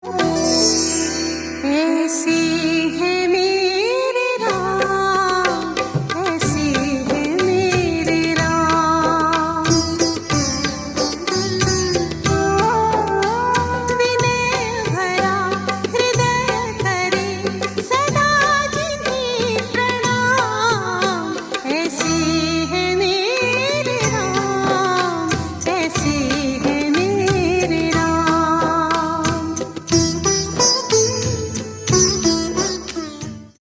• Category: Devotional / Bhajan
• Mood: Peaceful, Spiritual, Emotional
• Calm and devotional sound
• No loud beats, pure bhakti feeling
A. It is a peaceful Shri Ram bhajan ringtone.